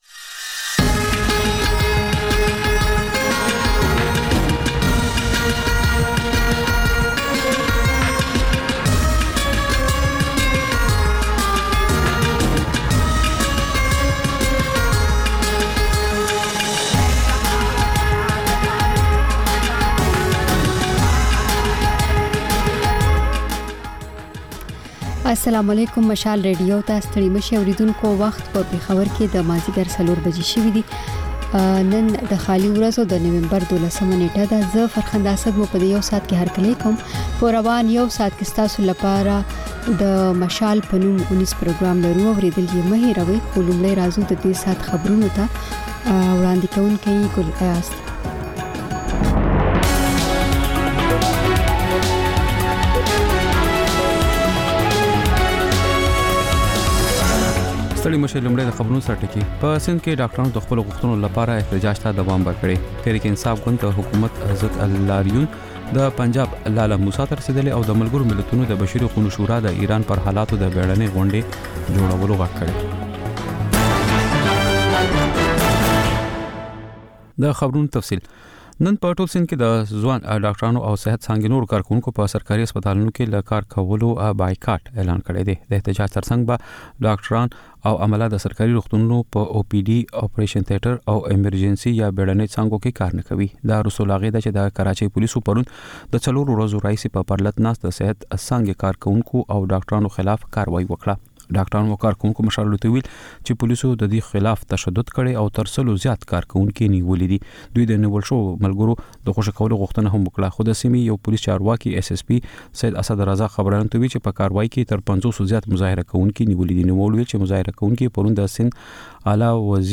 د مشال راډیو مازیګرنۍ خپرونه. د خپرونې پیل له خبرونو کېږي، بیا ورپسې رپورټونه خپرېږي.